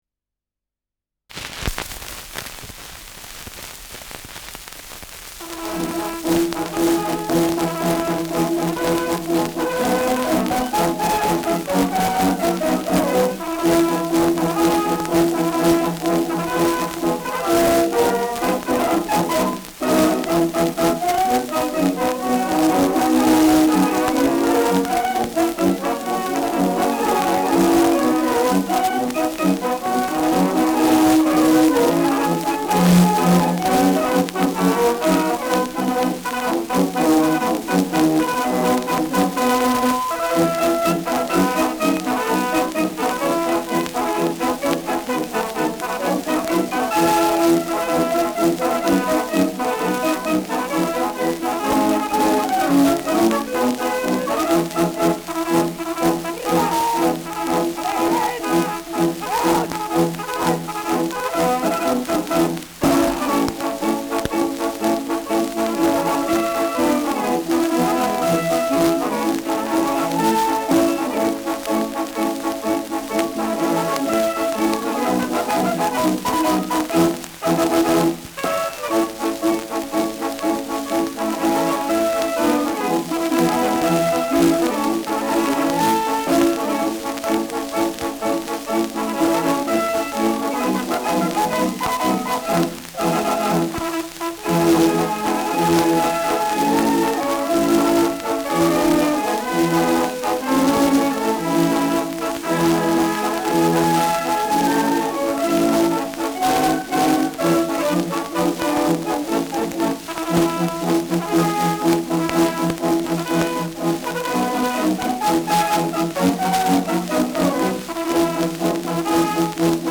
Schellackplatte
Tonrille: Kratzer Durchgehend Stärker
präsentes Rauschen
Kapelle Peuppus, München (Interpretation)